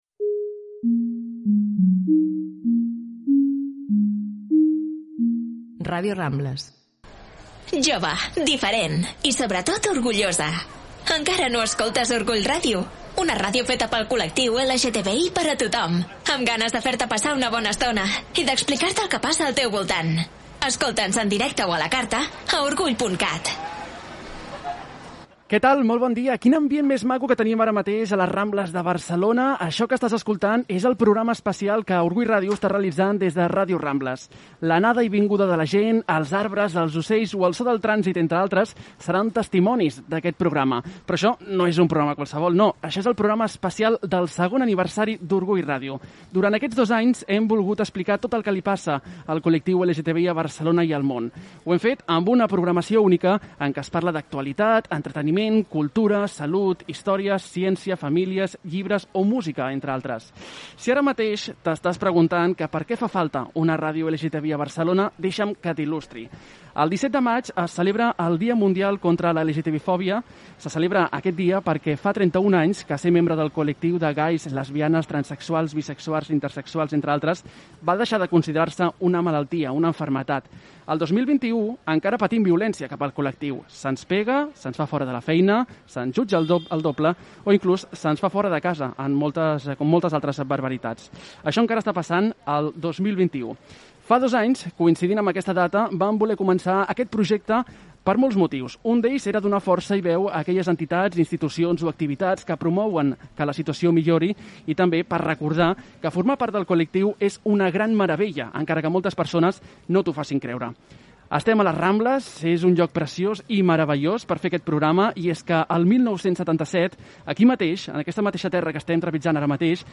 Intervenció de dos col·laboradors de la ràdio